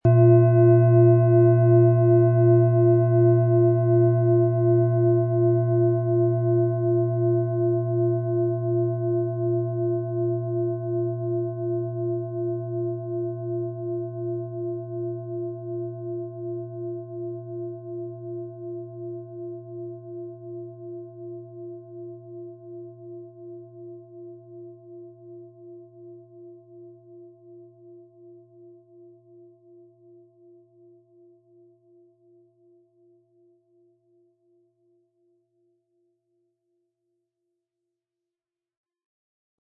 Von erfahrenen Meisterhänden in Handarbeit getriebene Klangschale.
• Tiefster Ton: Mond
Wie klingt diese tibetische Klangschale mit dem Planetenton Chiron?
PlanetentöneChiron & Mond
MaterialBronze